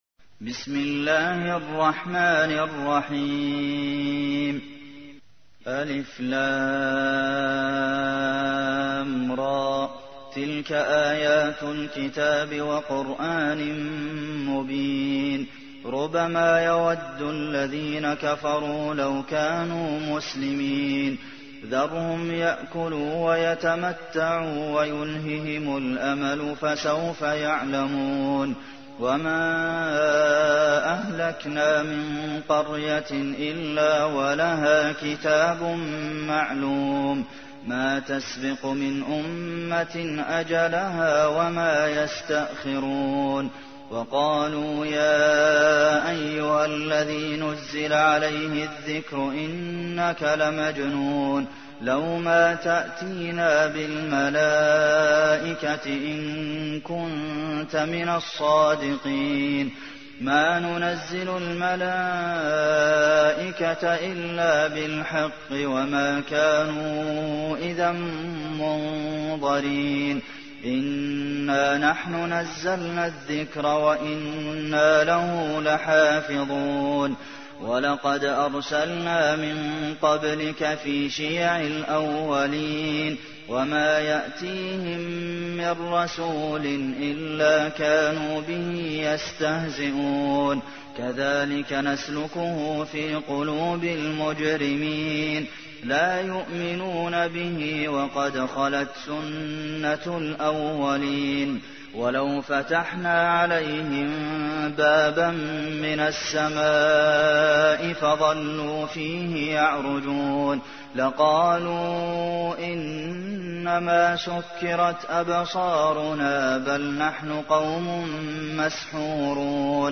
تحميل : 15. سورة الحجر / القارئ عبد المحسن قاسم / القرآن الكريم / موقع يا حسين